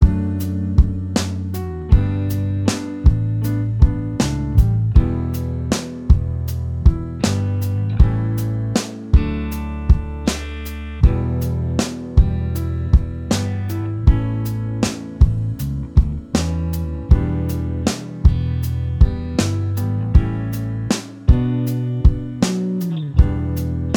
Minus All Guitars Pop